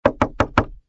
GUI_knock_1.ogg